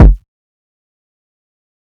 KICK - OPPOSITE.wav